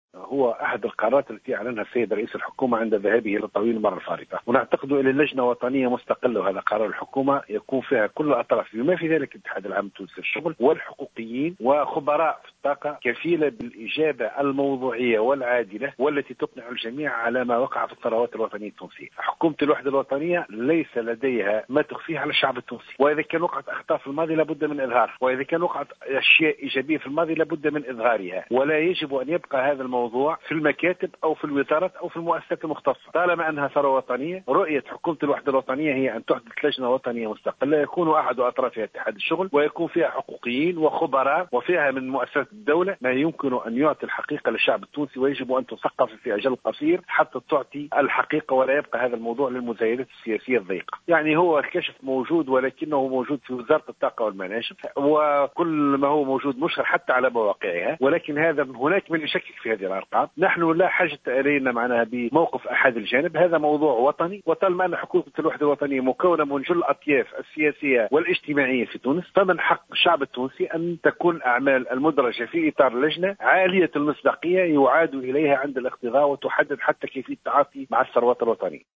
أعلن مبروك كورشيد، كاتب الدّولة لدى وزارة المالية المكلف بأملاك الدّولة والشؤون العقارية، في تصريح للجوهرة أف أم، اليوم الثلاثاء، عن تأسيس لجنة وطنية مستقلة للتحقيق في الثروات الطبيعية بمشاركة خبراء في الطاقة وحقوقيين بالإضافة إلى الاتحاد العام التونسي للشغل.